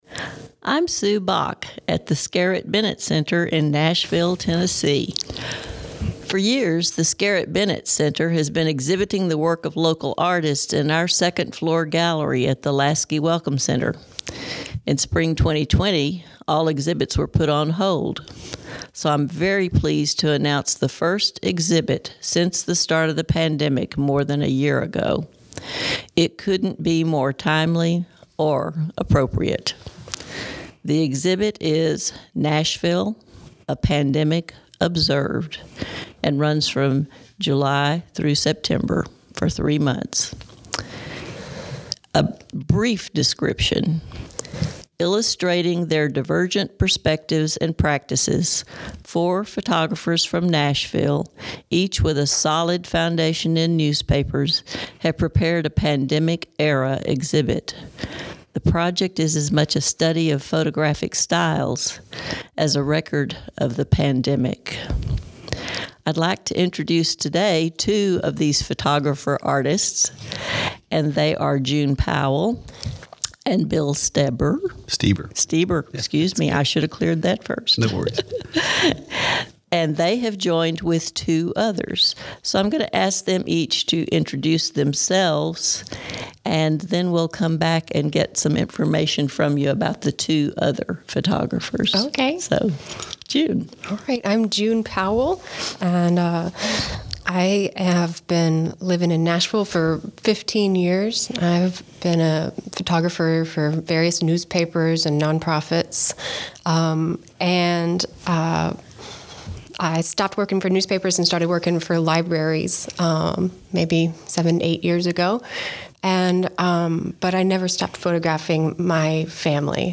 Listen to an interview with the artists:
Pandemic-Artist-Interview-June-2021.mp3